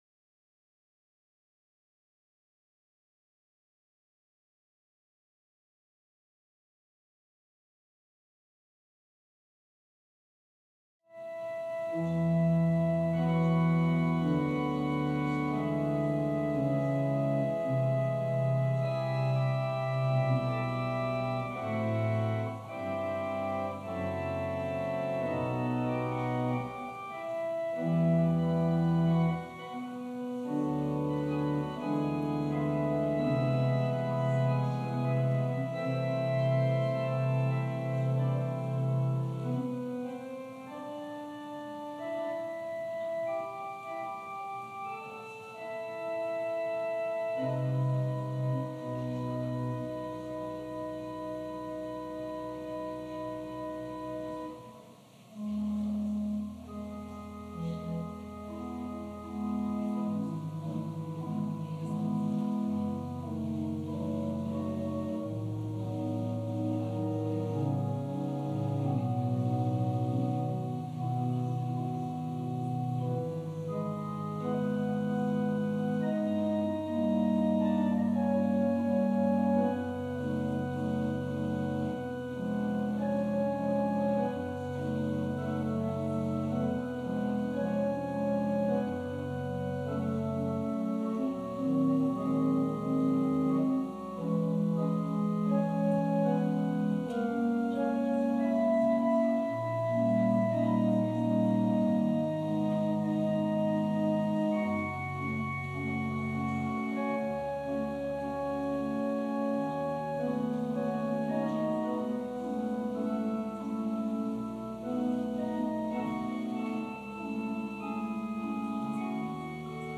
Passage: Luke 6: 17-26 Service Type: Sunday Service Scriptures and sermon from St. John’s Presbyterian Church on Sunday